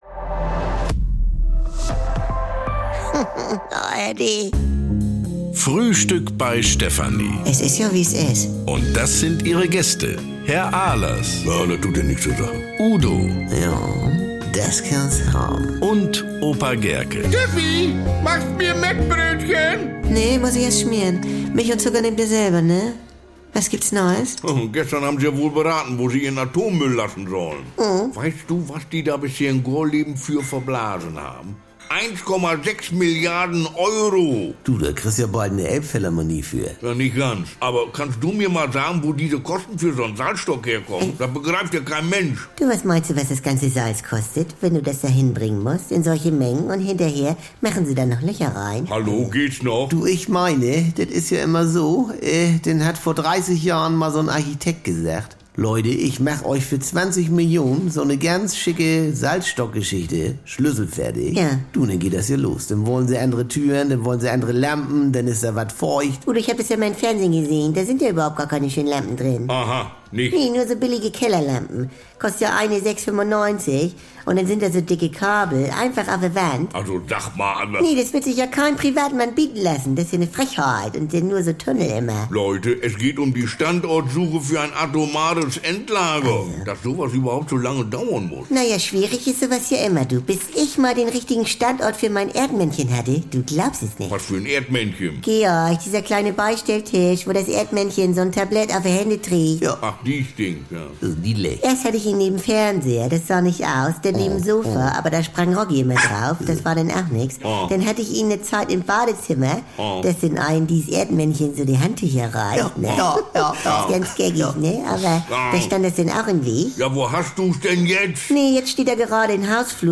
Garantiert norddeutsch mit trockenen Kommentaren, deftigem Humor und leckeren Missverständnissen.